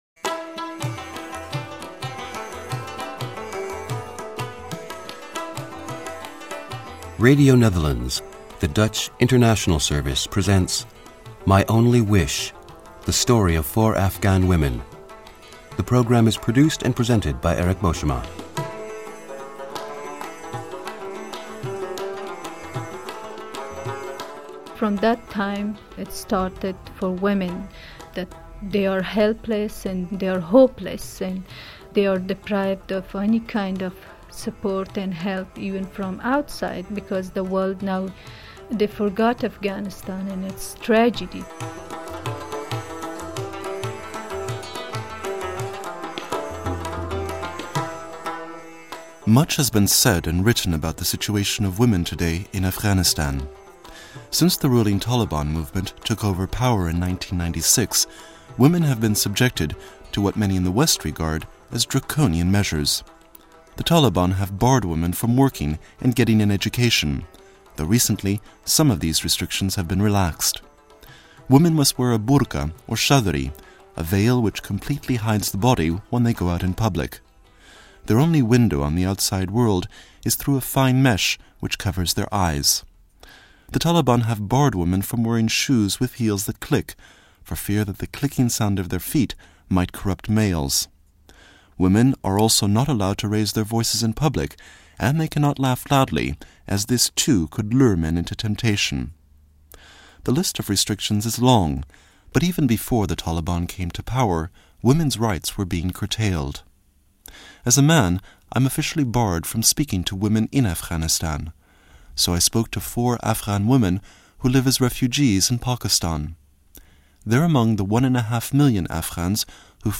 Four Afghan women explain what it’s like to be a woman in Afghanistan.